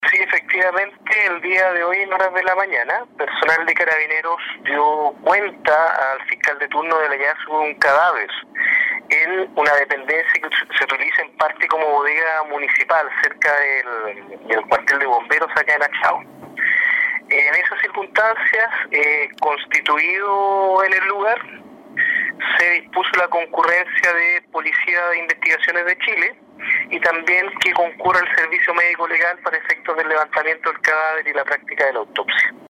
Al momento del hallazgo se encontraba en el suelo en dependencias que corresponden a bodegas pertenecientes a la municipalidad de Quinchao, según informó el fiscal Cristian Mena, a cargo precisamente de las diligencias en este caso.